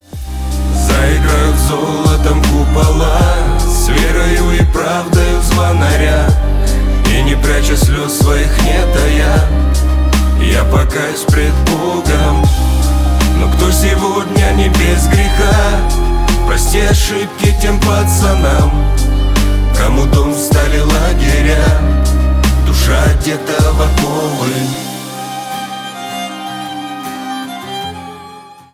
душевные
шансон , поп